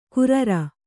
♪ kurara